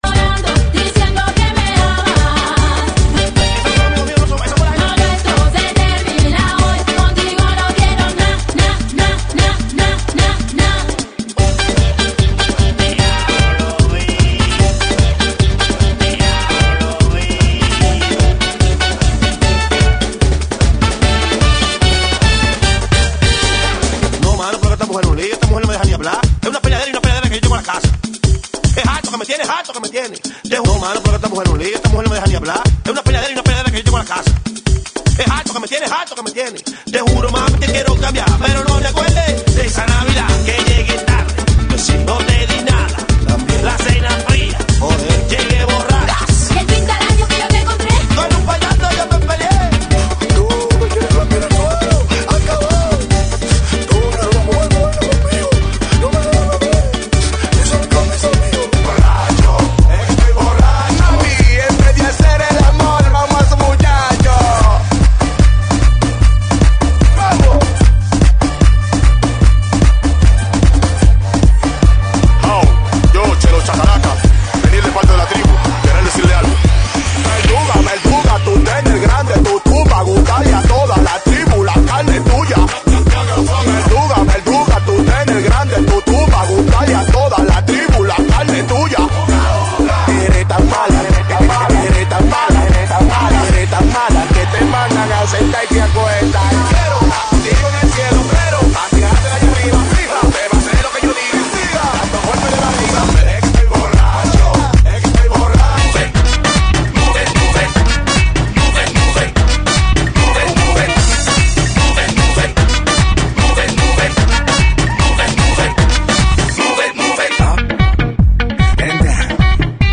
GENERO: LATINO URBANO